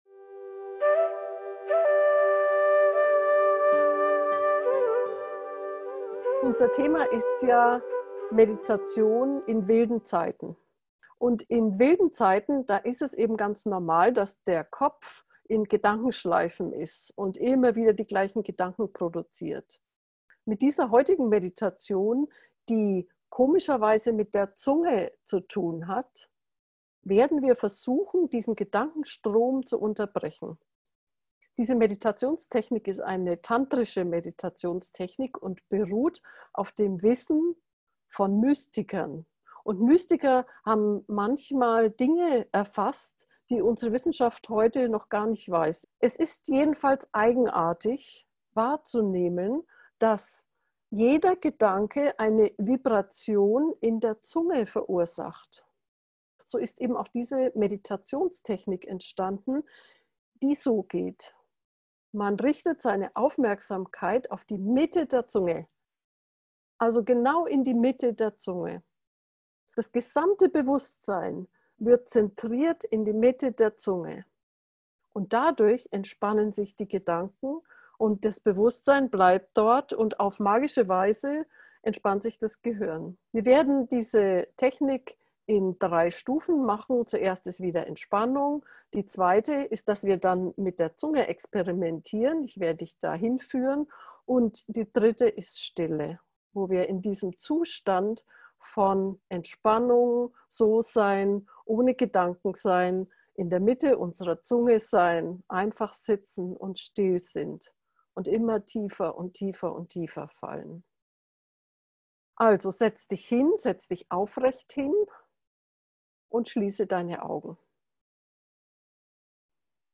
Geführte Meditationen